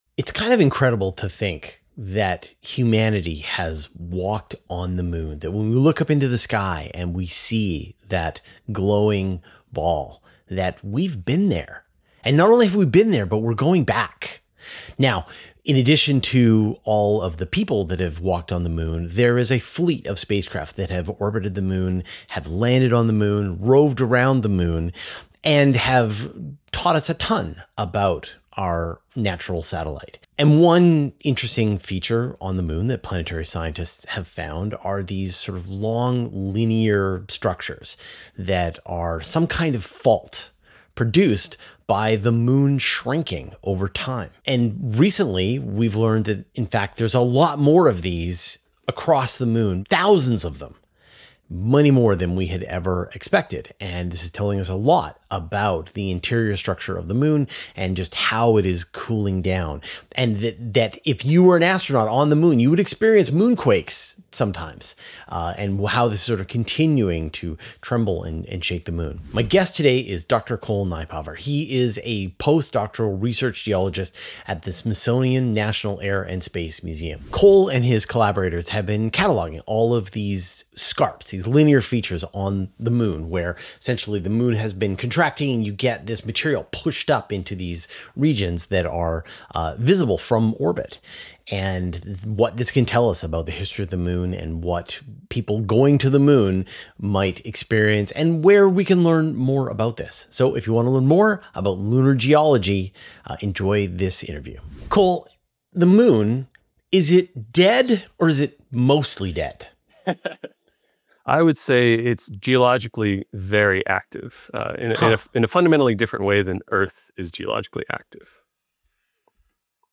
[Interview+] How Moon's Ridges Reveal Secrets About Its Geology from Universe Today Podcast | Podcast Episode on Podbay